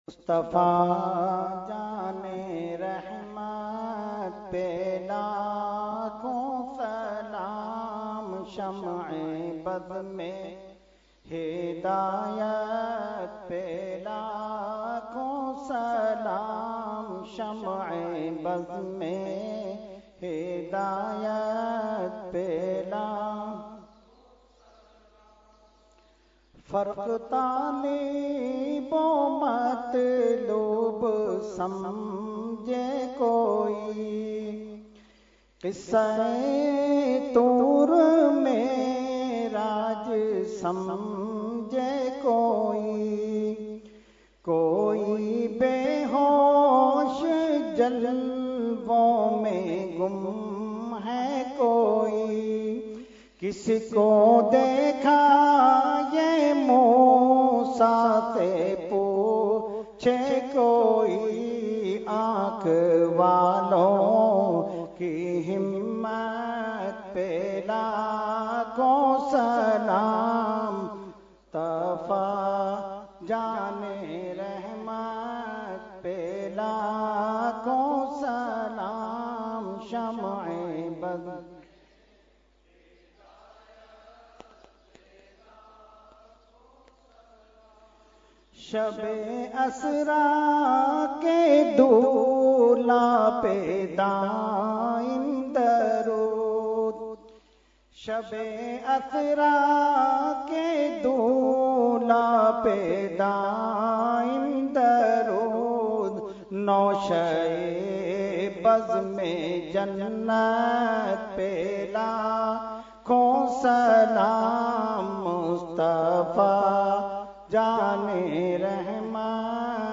Category : Salam | Language : UrduEvent : Shab e Meraj 2018